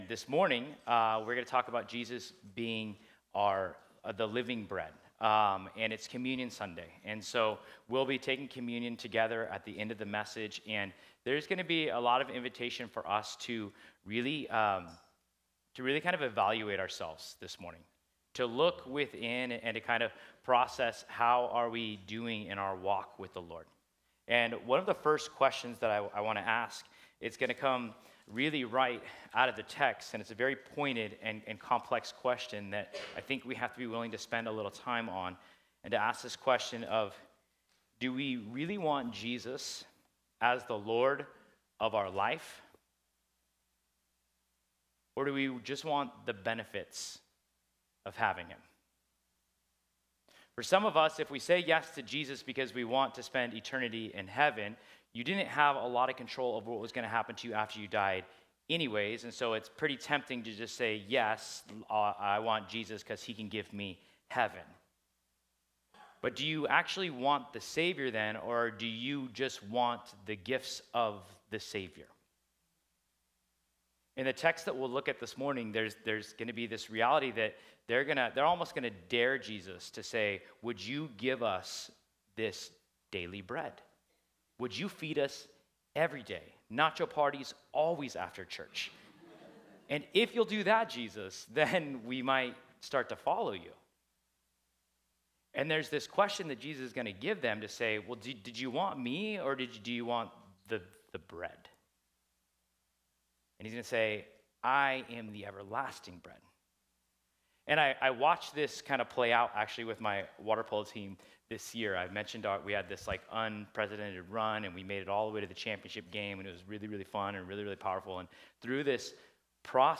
John 6 Service Type: Sunday This Sunday